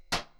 hitMetal3.wav